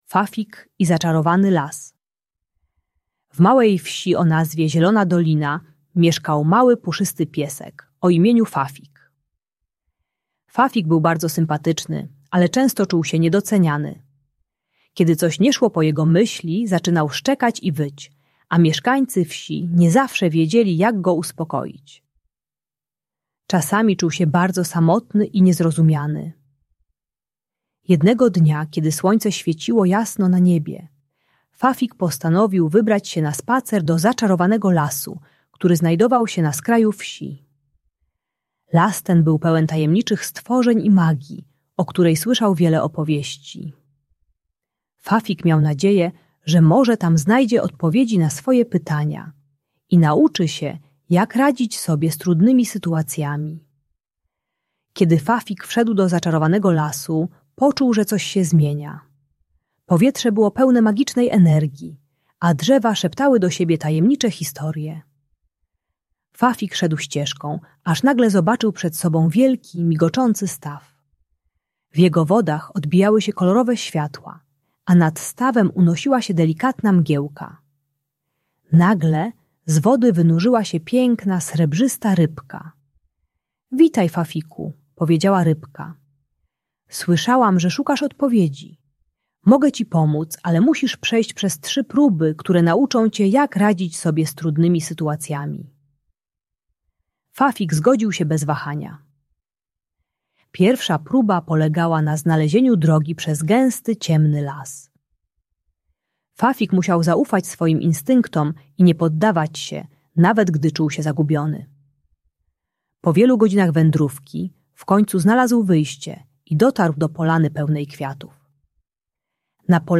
Fafik i Zaczarowany Las - Niepokojące zachowania | Audiobajka